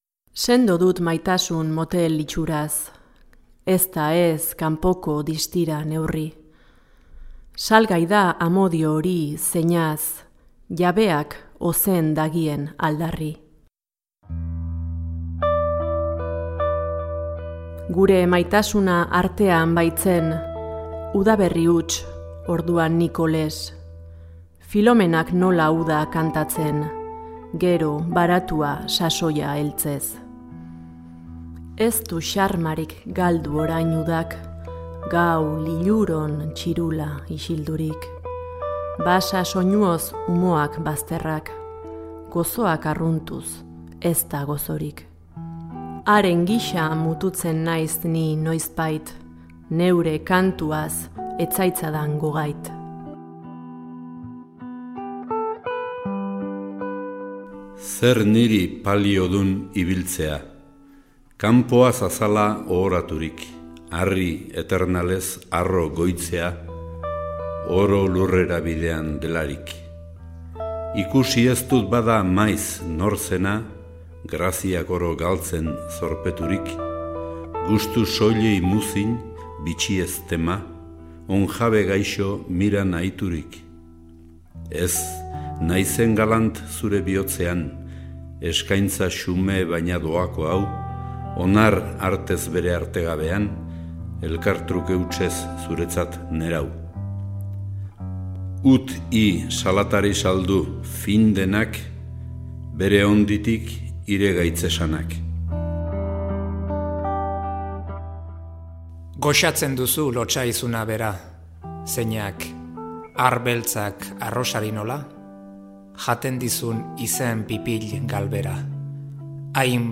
Las grabaciones se han llevado a cabo en su mayor parte en la Biblioteca Carlos Santamaría de UPV/EHU en San Sebastián.
Además, intercaladas entre las canciones mencionadas, hay músicas de fondo, acompañando a algunas de las recitaciones.
Recitados